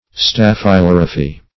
staphylorrhaphy - definition of staphylorrhaphy - synonyms, pronunciation, spelling from Free Dictionary
\Staph`y*lor"rha*phy\ (-l[o^]r"[.a]*f[y^]), n. [Gr. stafylh` the